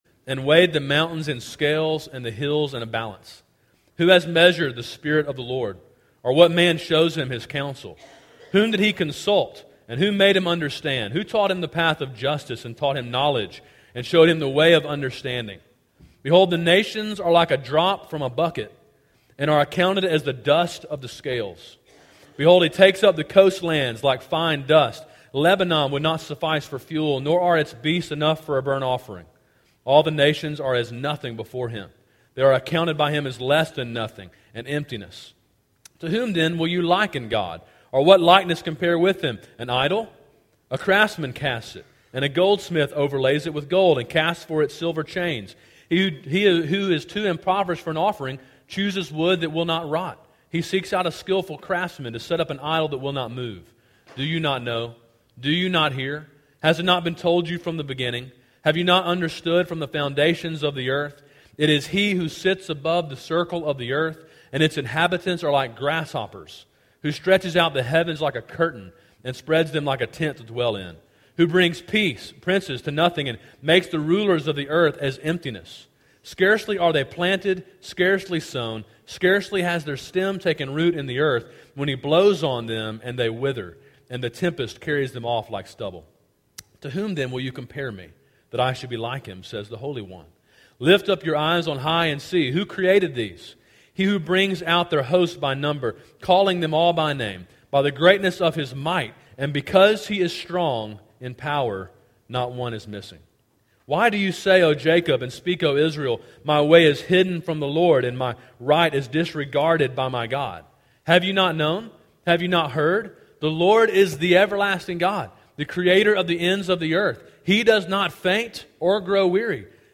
Sermon: “God Is Strong” (Isaiah 40:12-31)